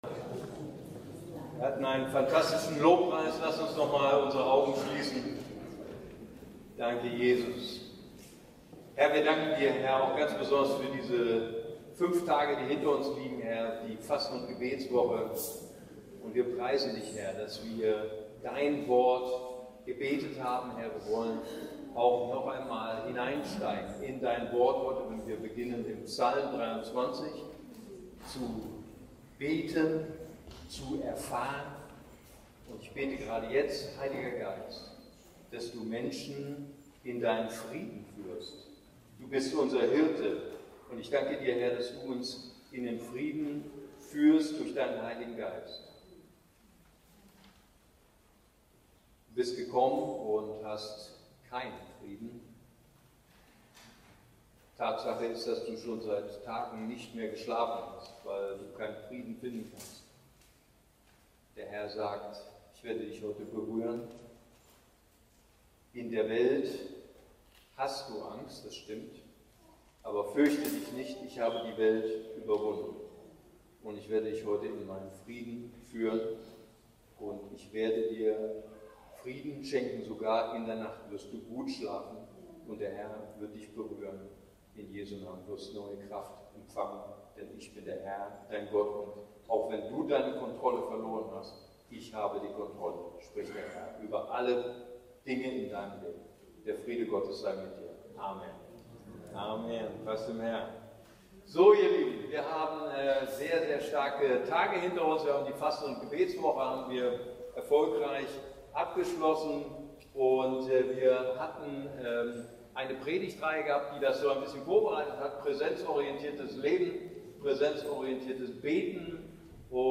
ICB Predigtreihe Herbst: Psalm 23 – Der Herr ist mein Hirte